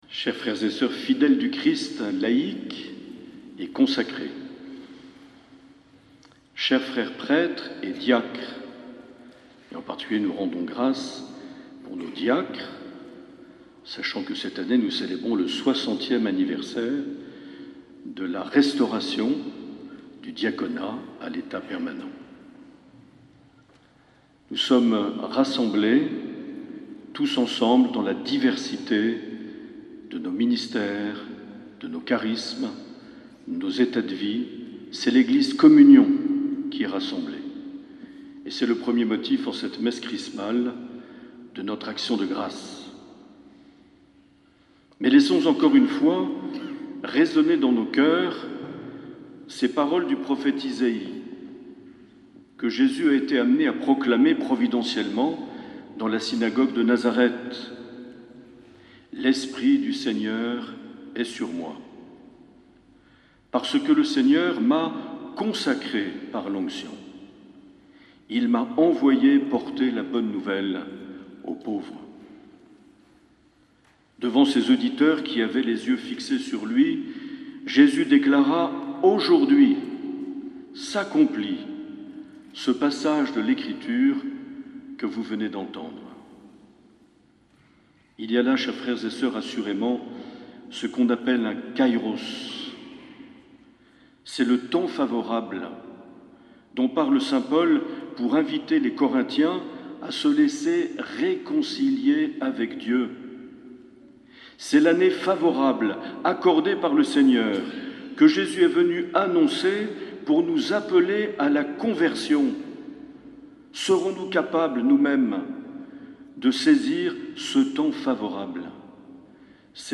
26 mars 2024 - Messe Chrismale - Cathédrale d'Oloron
Homélie de Mgr Marc Aillet.